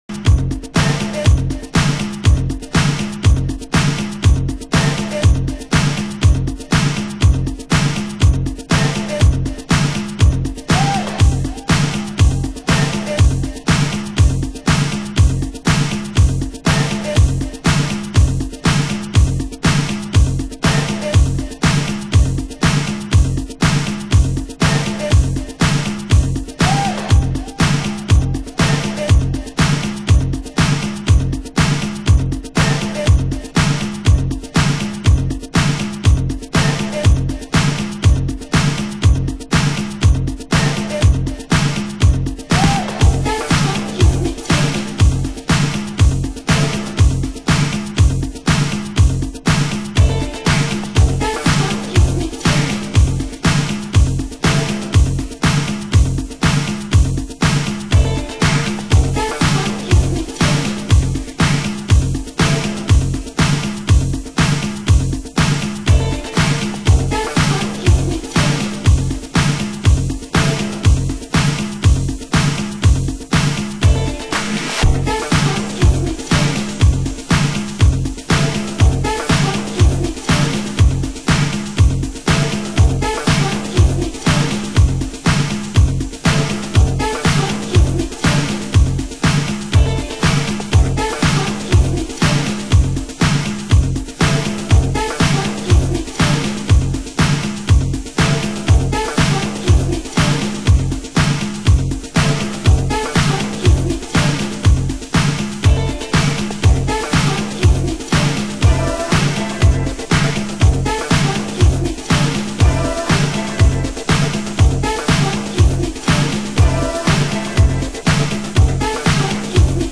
★DISOCDUB NUHOUSE